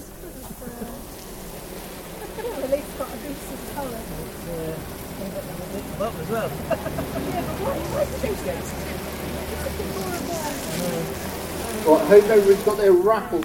Honey Stall and bees at the Holbeach St Johns village fete